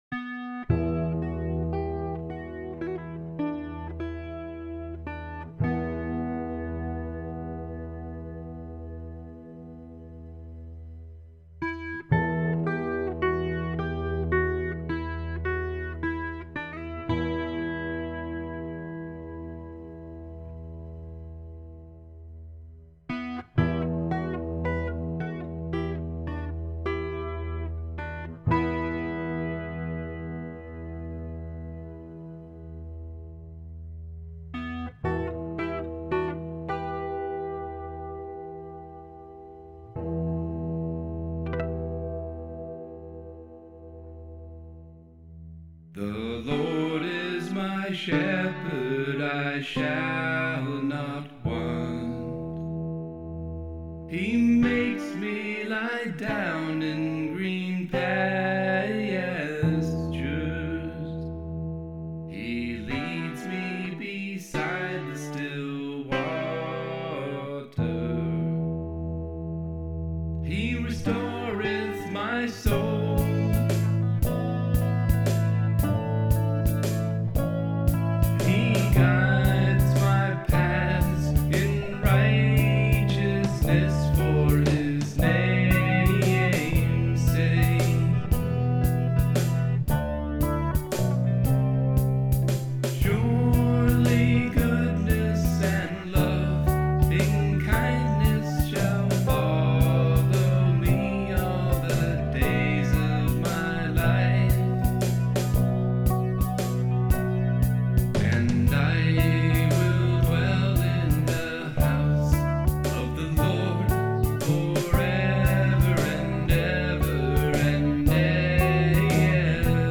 Here's a song I recorded through Mixcraft 7.
guitar